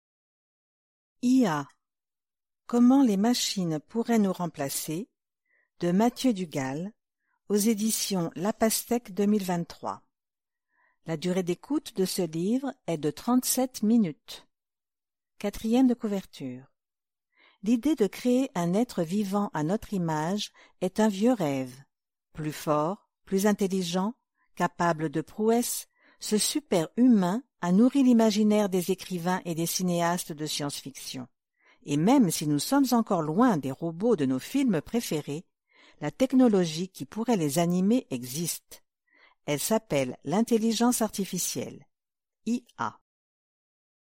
QUELQUES EXEMPLES DE LECTURE DES AUDIOLIVRES :